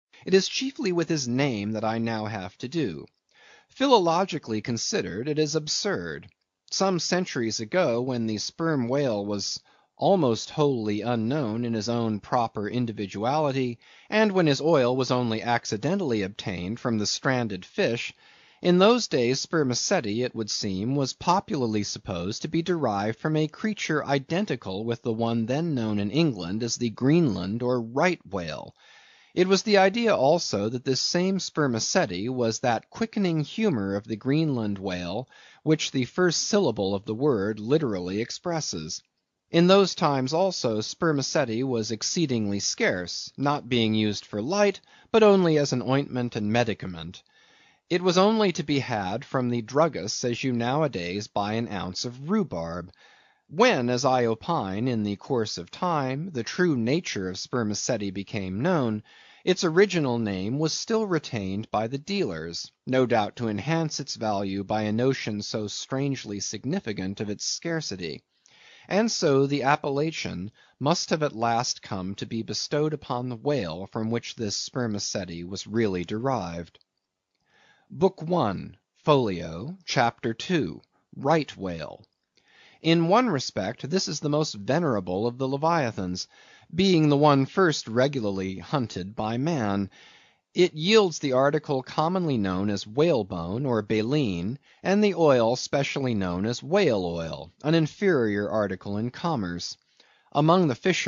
英语听书《白鲸记》第374期 听力文件下载—在线英语听力室